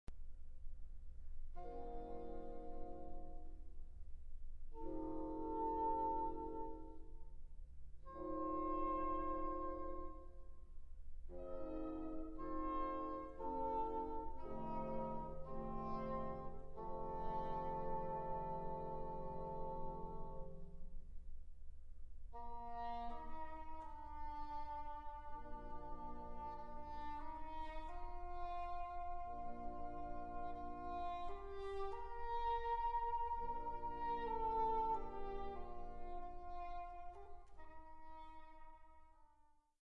Aria